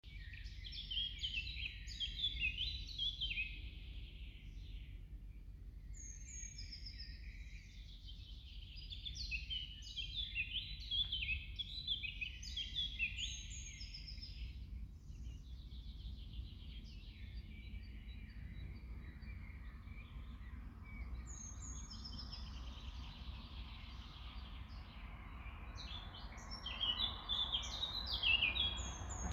черноголовая славка, Sylvia atricapilla
Administratīvā teritorijaRīga
СтатусПоёт
ПримечанияZilzīlīti tā kā pazīstu, bet vai tas otrais ir melngalvas ķauķis?